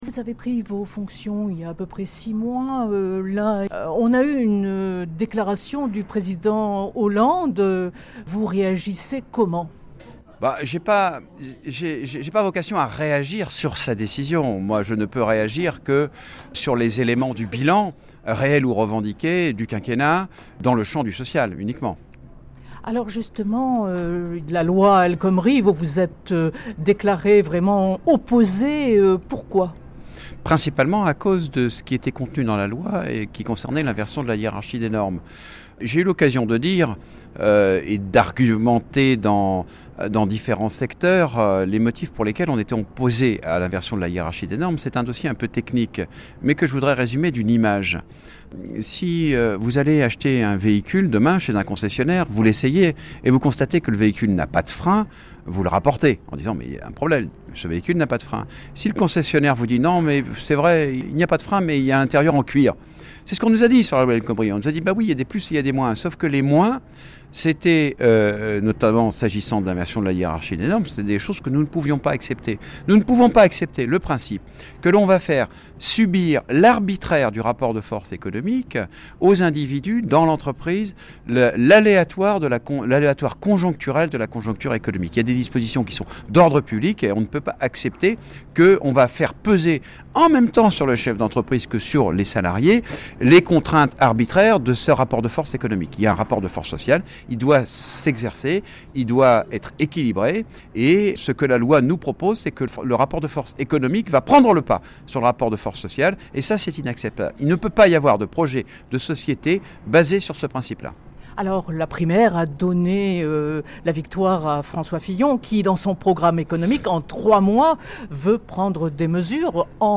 Entretien : François Hommeril président de la CFE-CGC : “une régression néolibérale qui crée de la souffrance”